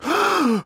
Притворный наигранный мужской испуг
pritvornij_naigrannij_muzhskoj_ispug_ck2.mp3